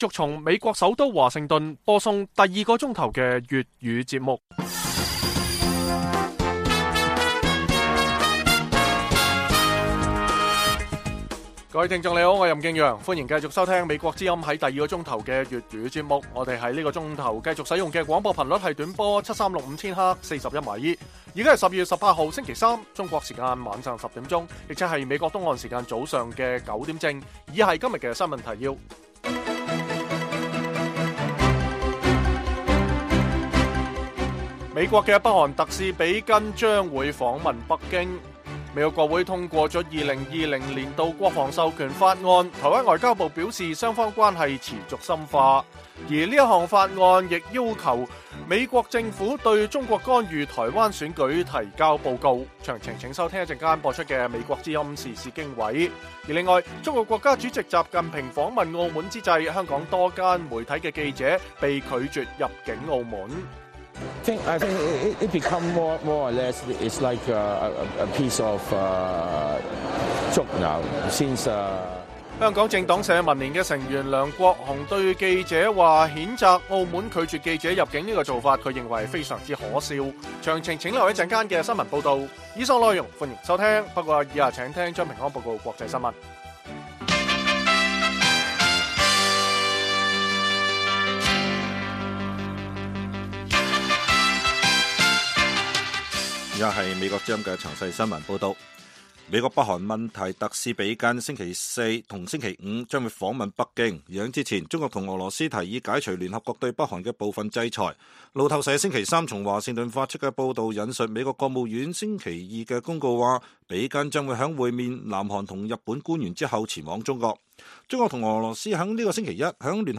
粵語新聞 晚上10-11點
北京時間每晚10－11點 (1400-1500 UTC)粵語廣播節目。內容包括國際新聞、時事經緯和社論。